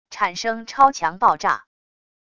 产生超强爆炸wav音频